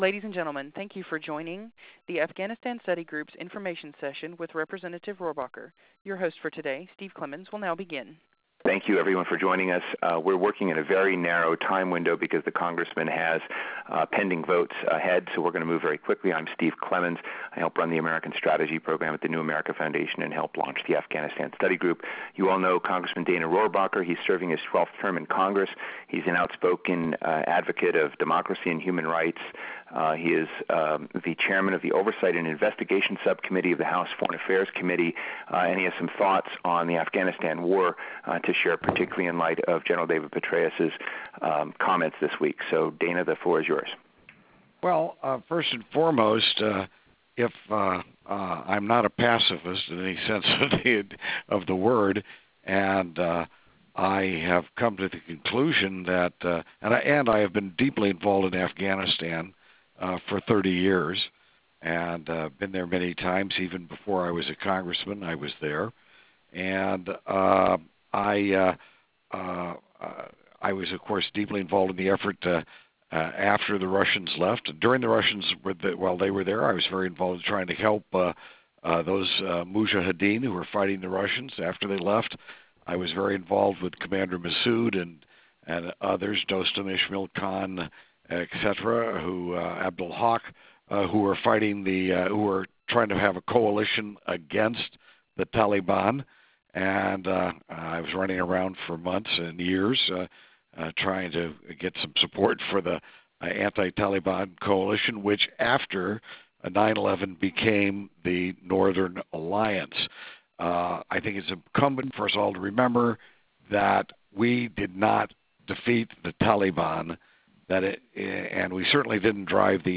Conference-Call-with-Rep.-Rohrabacher.wav